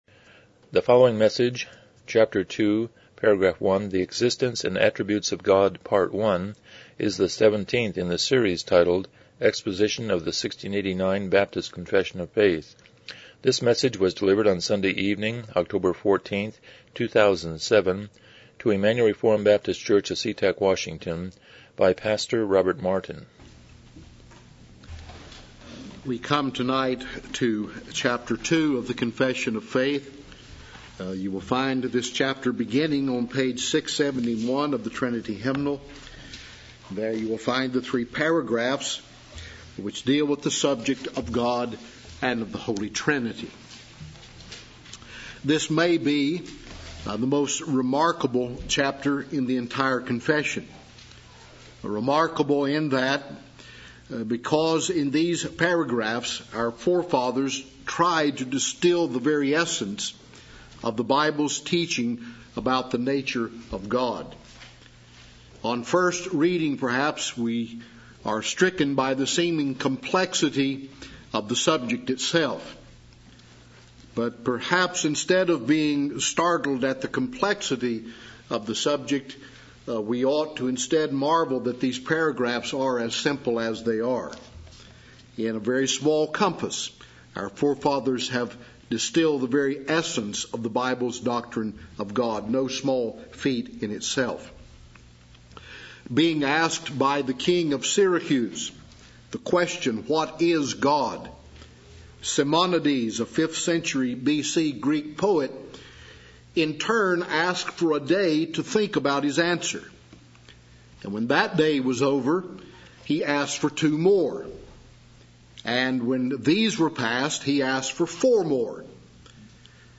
Series: 1689 Confession of Faith Service Type: Evening Worship